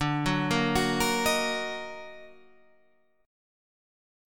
Ebm/D chord